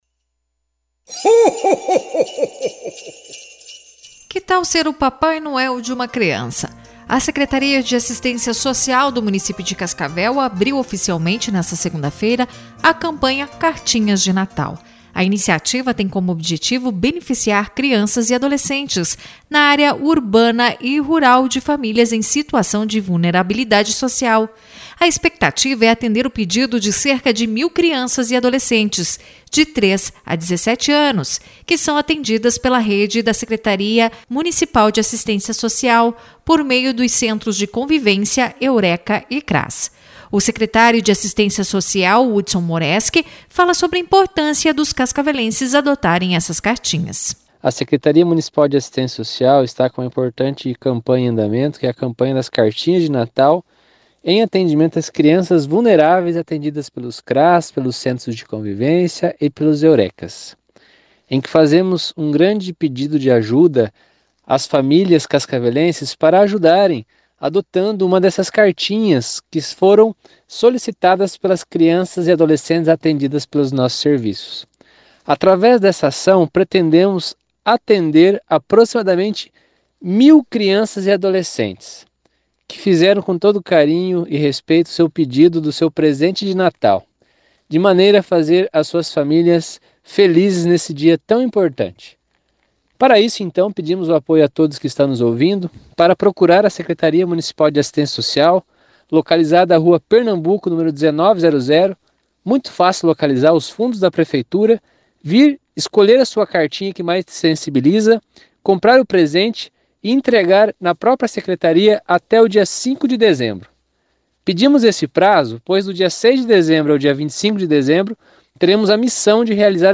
O secretário de Assistencia Social Hudson Moreski fala sobre a importância dos cascavelenses adotarem essas cartinhas.